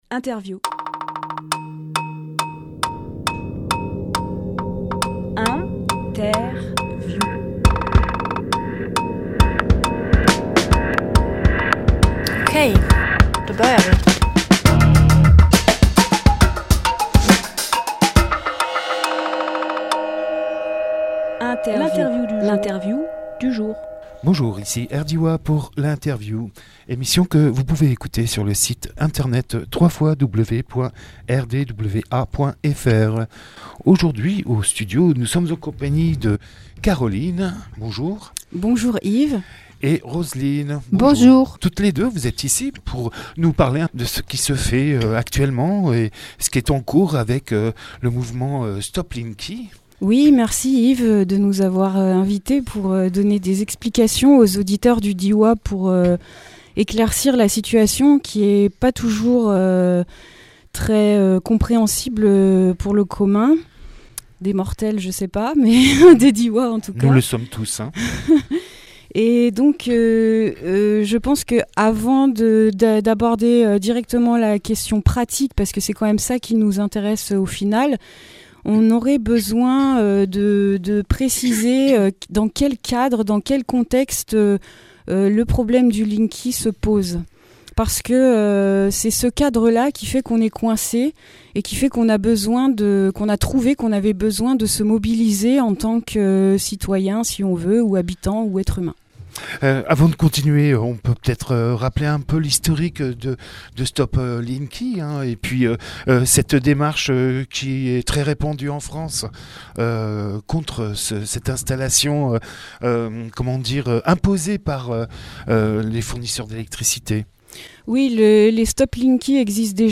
Emission - Interview Actualités de Stop linky Diois Publié le 29 novembre 2018 Partager sur…
Lieu : Studio RDWA